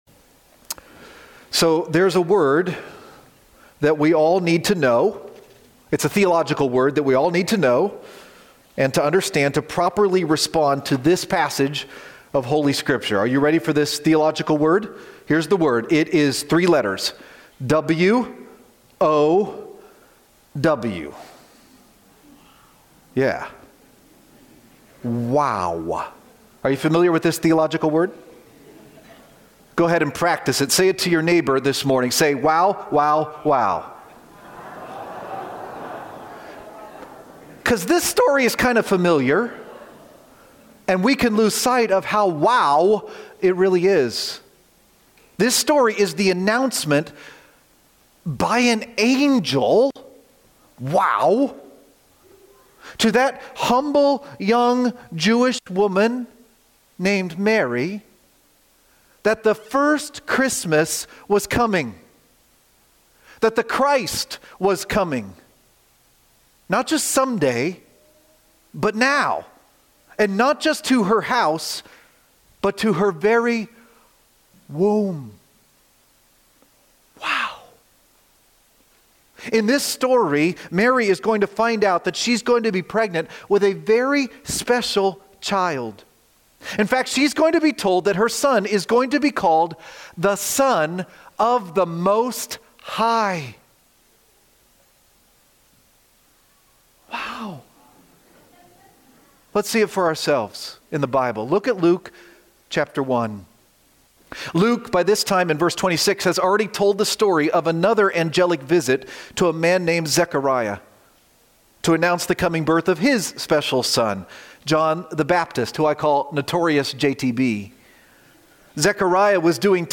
The Son of the Most High :: December 21, 2025 - Lanse Free Church :: Lanse, PA